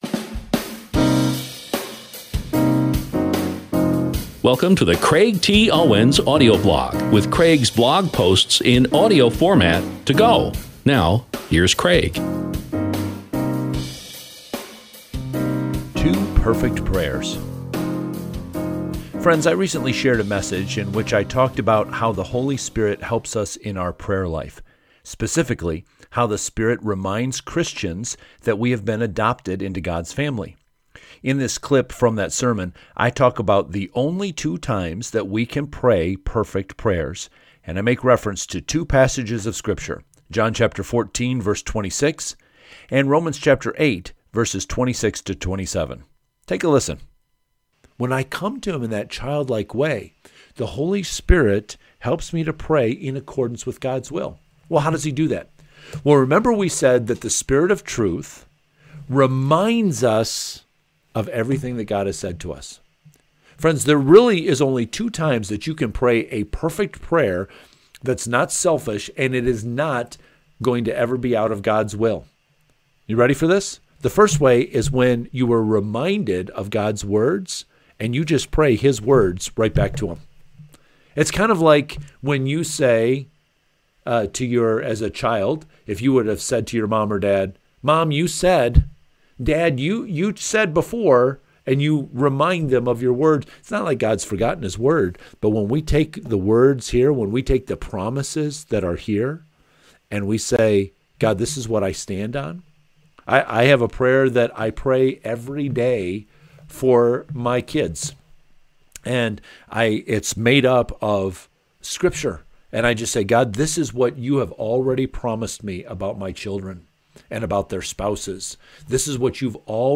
In this clip from that sermon, I talk about the only two times that we can pray perfect prayers, and I make reference to two passages of Scripture: John 14:26 and Romans 8:26-27.